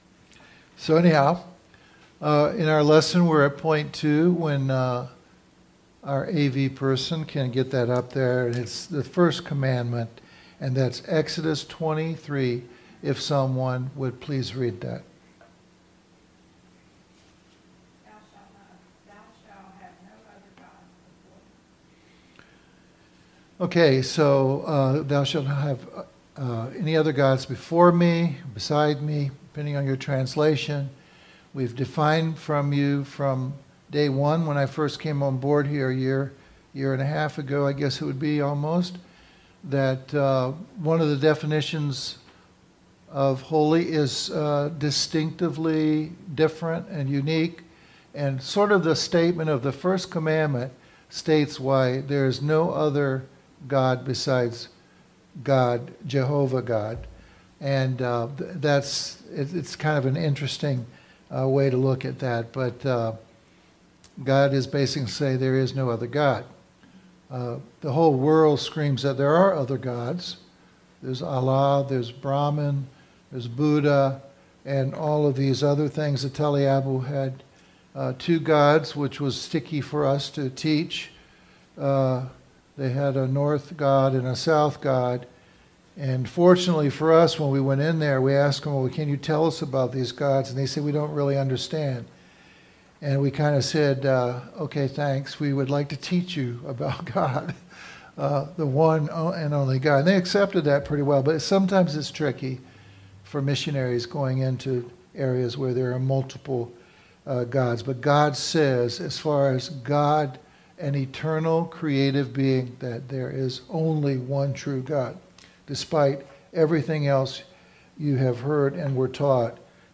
Service Type: Firm Foundations